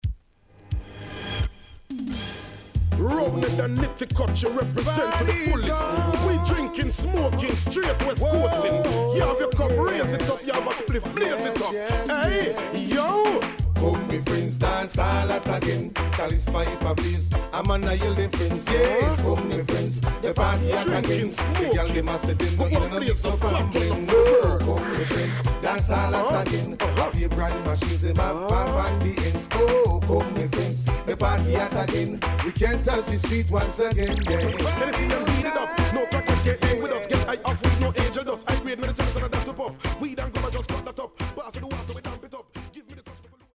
HOME > LP [DANCEHALL]
SIDE A:少しチリノイズ、プチノイズ入ります。